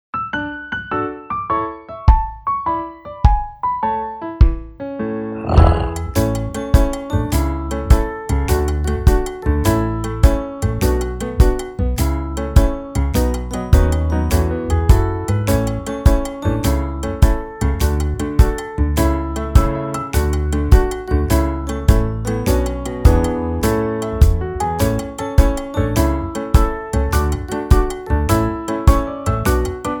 utwór w wersji instrumentalnej